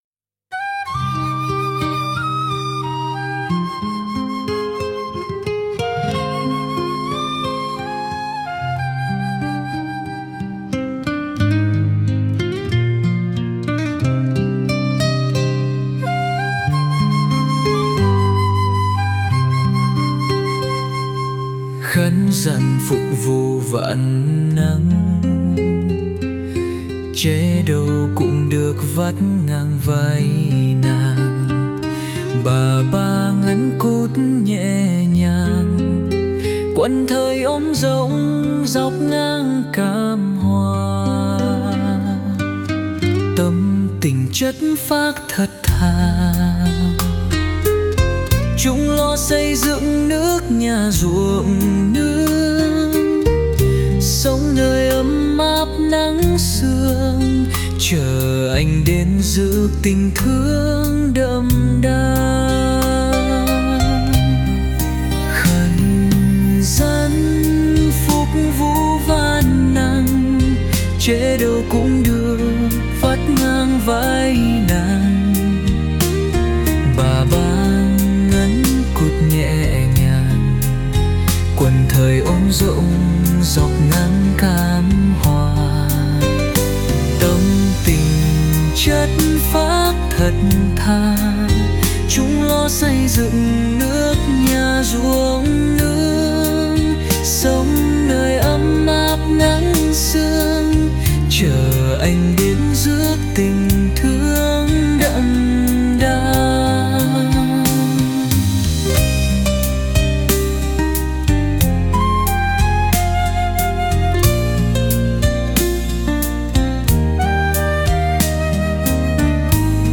NHẠC THƠ- ÁO BÀ BA-ĐỨC THẦY LƯƠNG SĨ HẰNG